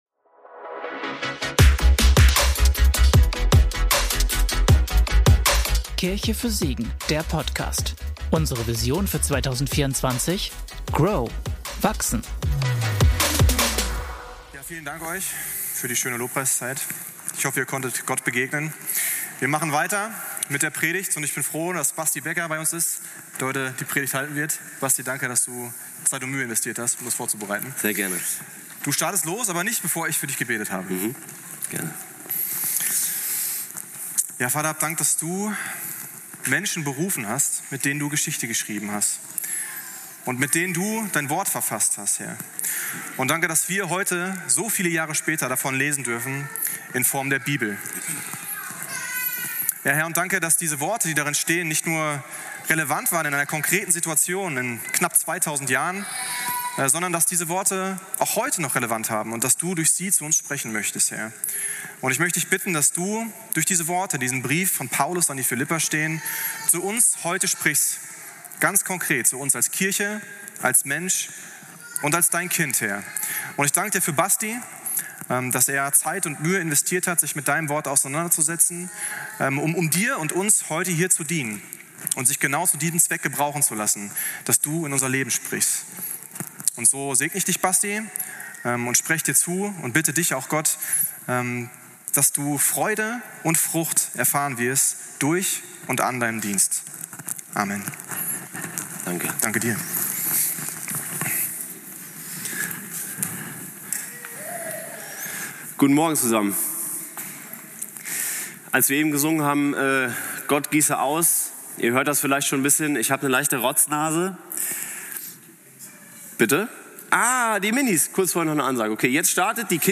Philipperbrief - Predigtpodcast